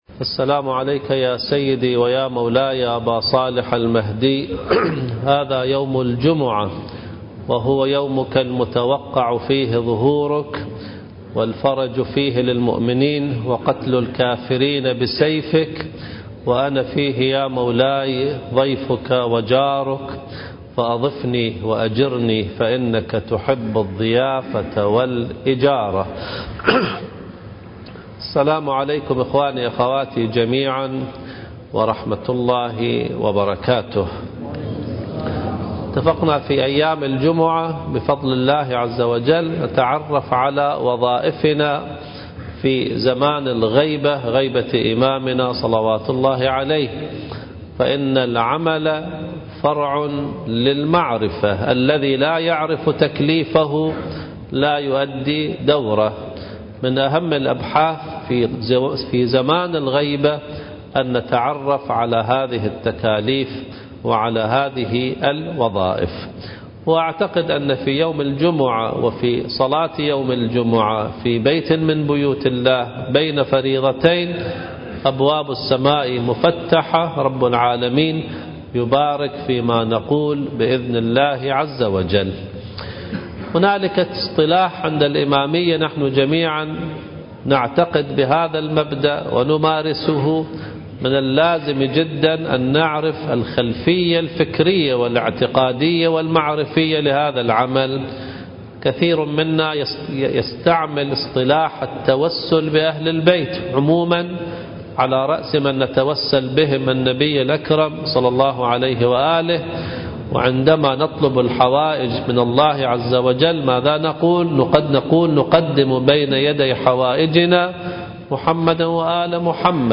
واجباتنا في زمن الغيبة (3) حديث الجمعة من خطبة الجمعة